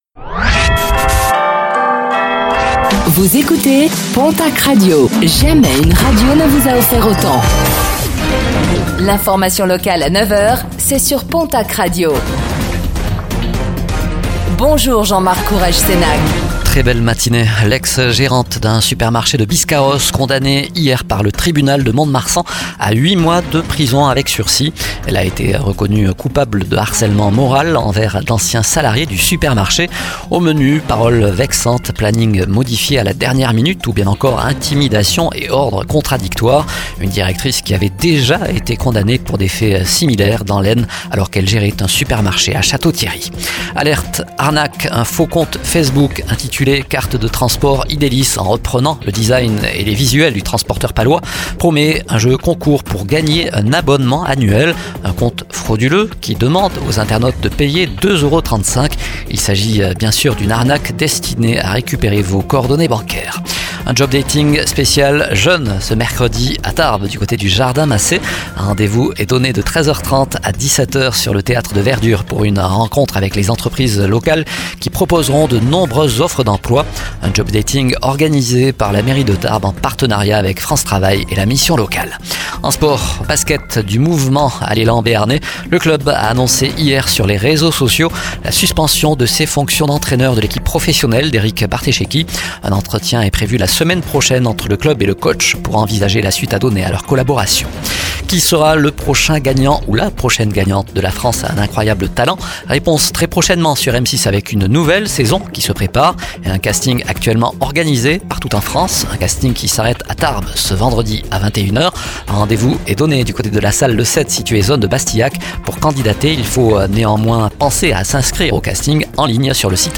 Infos | Mercredi 22 mai 2024